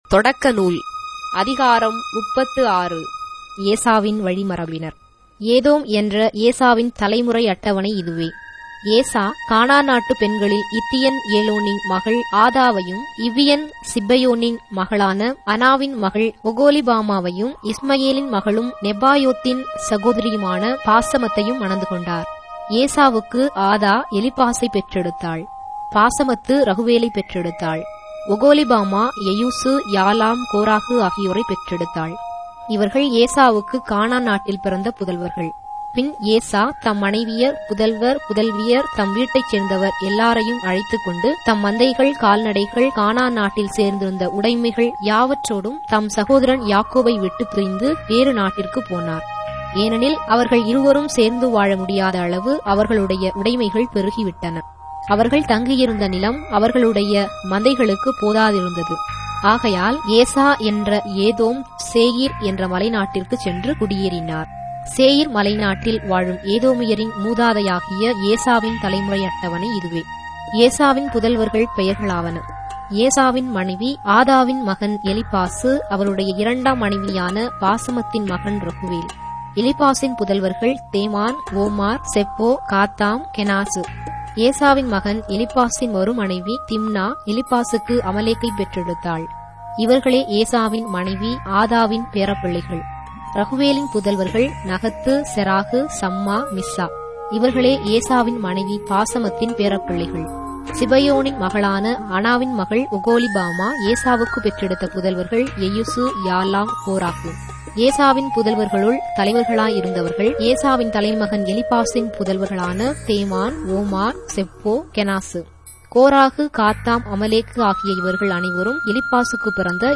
Tamil Audio Bible - Genesis 10 in Ecta bible version